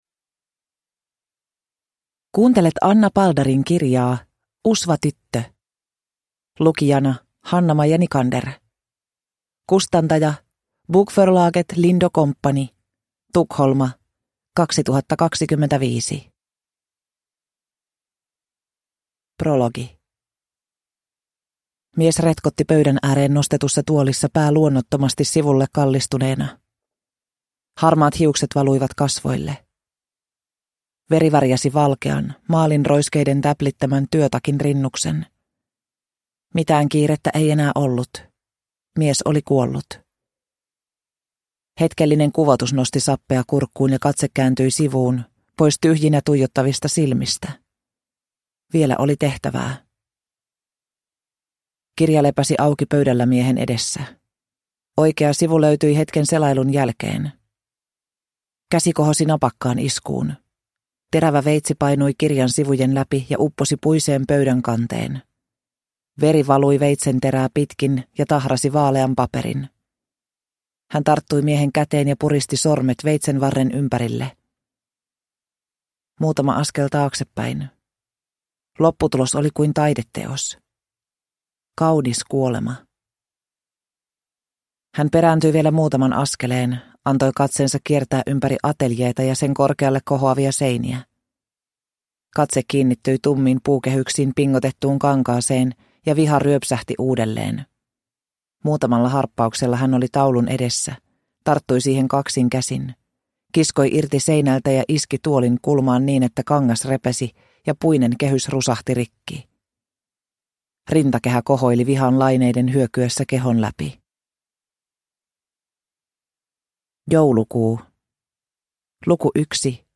Usvatyttö (ljudbok) av Anna Paldar | Bokon